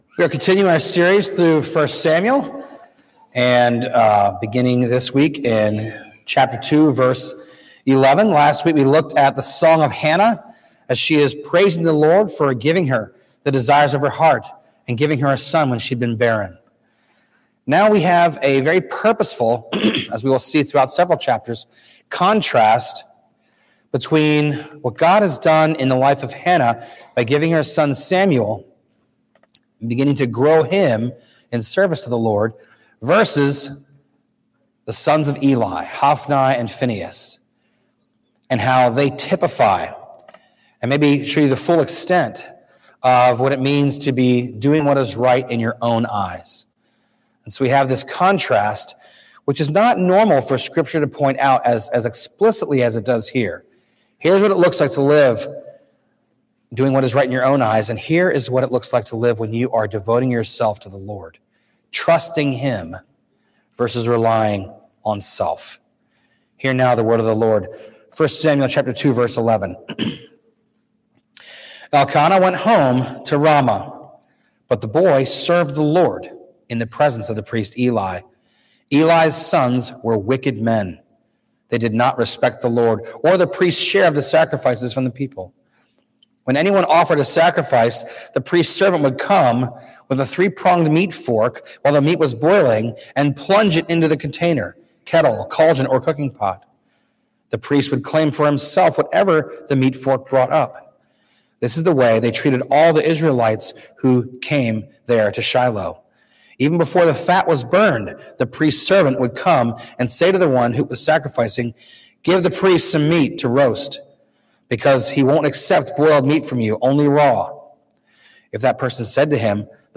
A message from the series "1 Samuel."